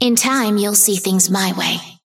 Vindicta voice line - In time, you'll see things my way.